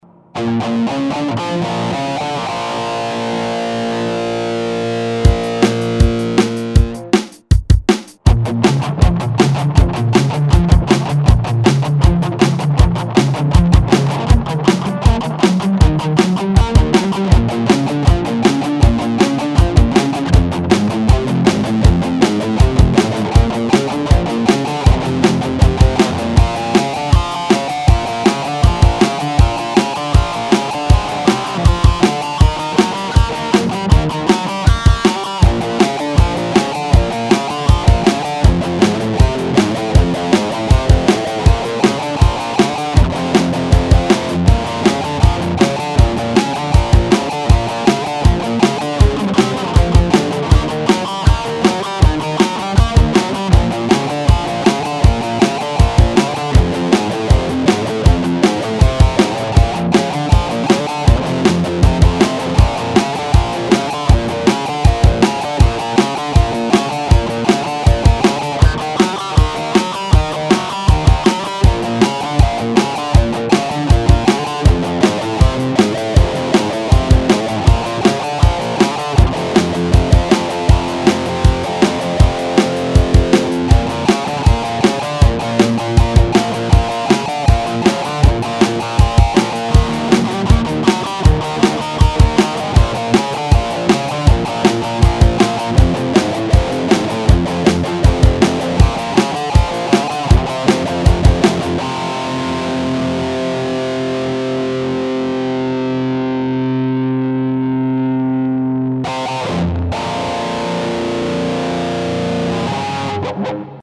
Пока получается только вот :-( Кроме того, не хватает баса и нормальных ударных.
NP> Типа рок-н-ролл [скачать]